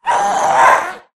scream5.ogg